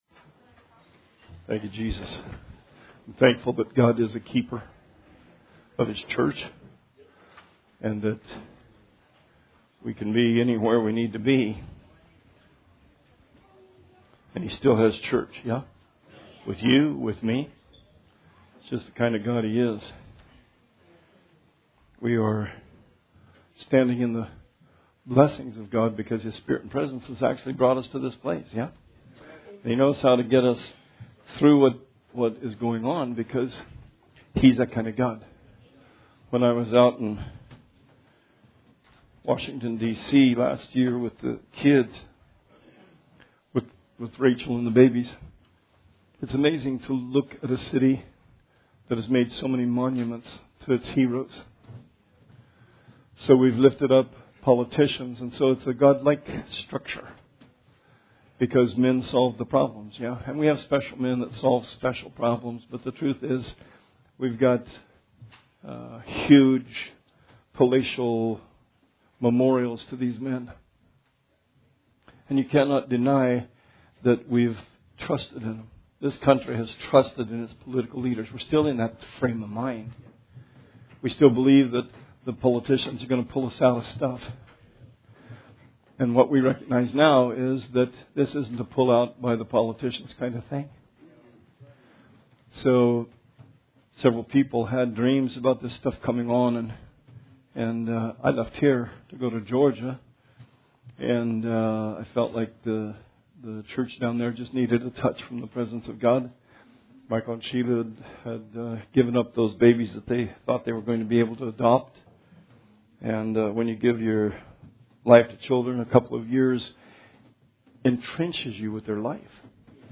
Sermon 3/15/20 – RR Archives